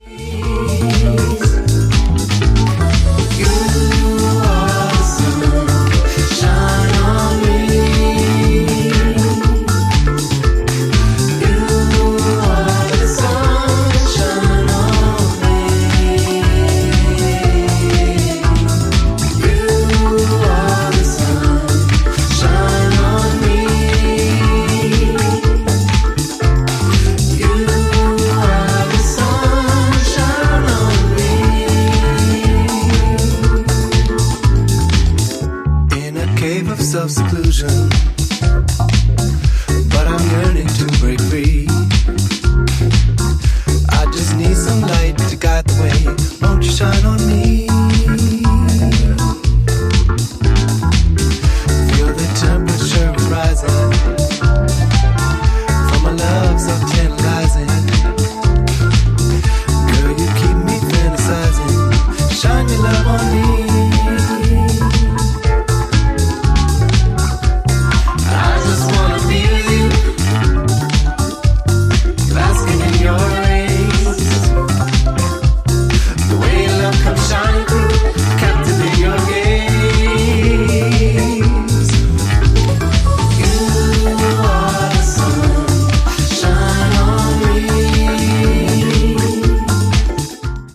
ジャンル(スタイル) NU DISCO / DISCO / BALEARICA / EDITS